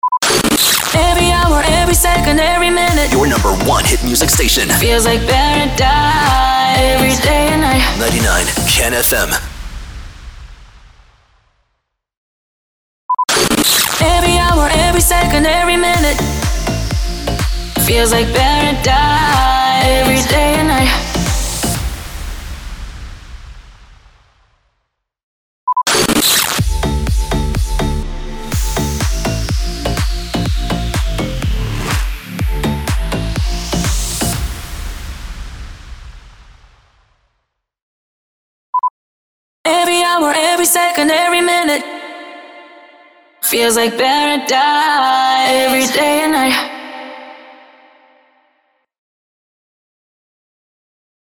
645 – SWEEPER – EVERY HOUR EVERY MINUTE